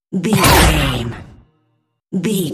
Dramatic hit laser
Sound Effects
Atonal
heavy
intense
dark
aggressive